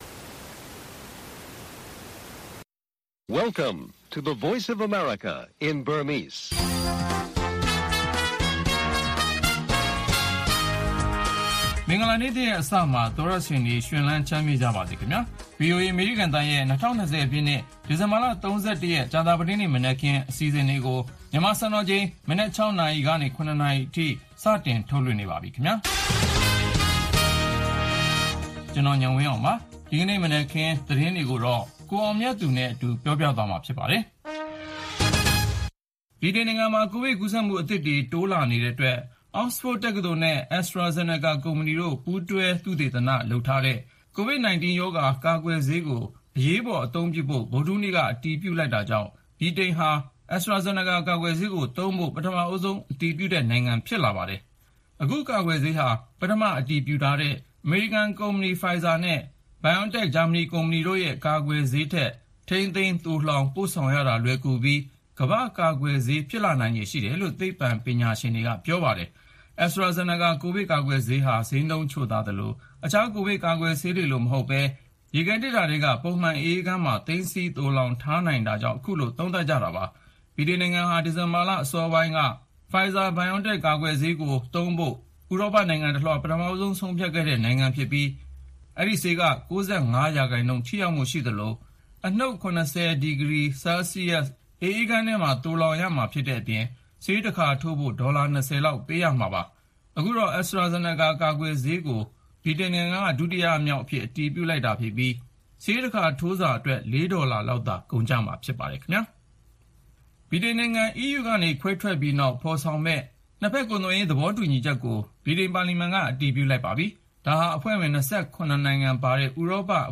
တိုင်းရင်းသားပါတီတွေနဲ့ ညှိုနှိုင်းဖို့ NLD ခေါင်းဆောင်တွေပါဝင်တဲ့ ကိုယ်စားလှယ်အဖွဲ့ ပထမဦးဆုံး ခရီးစဉ်အဖြစ် ကချင်ပြည်နယ် ကိုရောက်ရှိသွားတဲ့ သတင်းနဲ့အတူ နံနက် ၆း၀၀- ရး၀၀ နာရီ ရေဒီယိုအစီအစဉ်